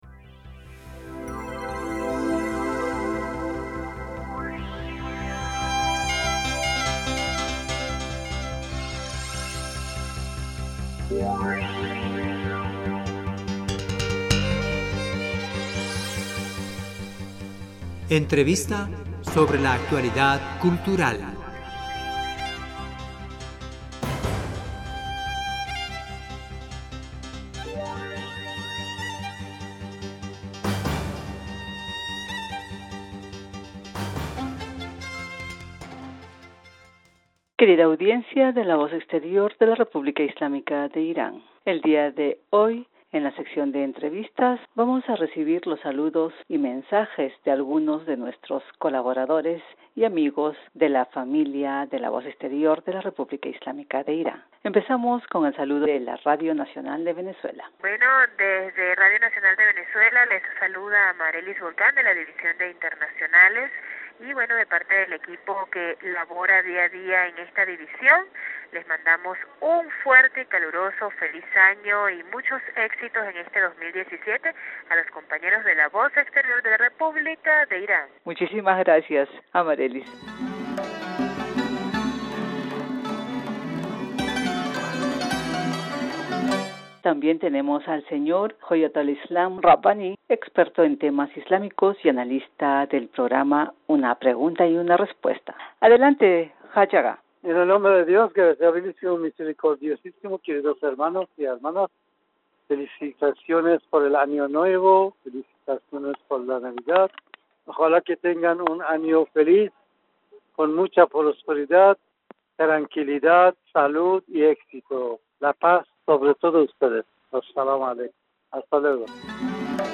Querida audiencia de la Voz exterior de la RII, el día de hoy en la sección de entrevistas vamos a recibir los saludos y mensajes de algunos de nuestros c...